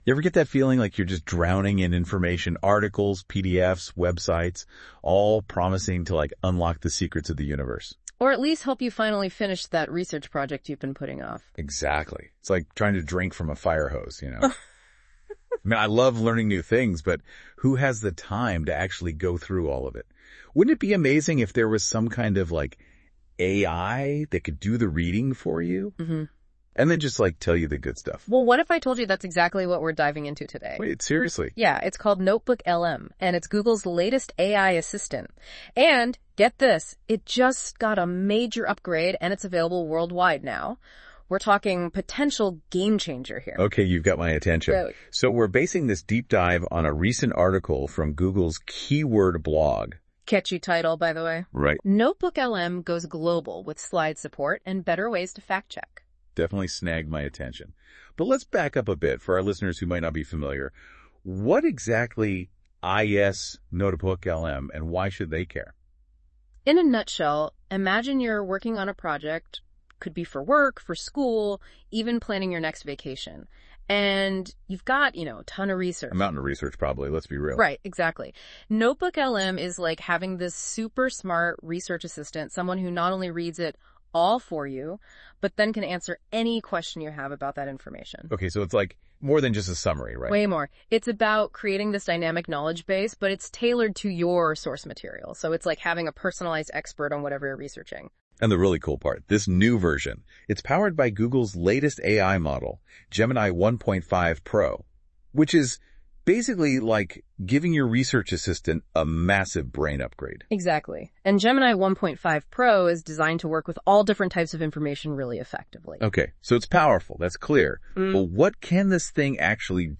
Funktionen kallas ”audio overview” och förvandlar dokument och källor till en diskussion mellan två digitala röster; som en slags podcast. I exemplet nedan har Google använt ett av företagets blogginlägg om Notebook LM som grund för den virtuella diskussionen.
With one click, two AI hosts start up a lively “deep dive” discussion based on your sources. They summarize your material, make connections between topics, and banter back and forth.
Rösterna är så pass övertygande att om man inte visste bättre hade man lätt kunnat tro att det var två människor som pratade.
En virtuell diskussion som skapats av Notebook LM: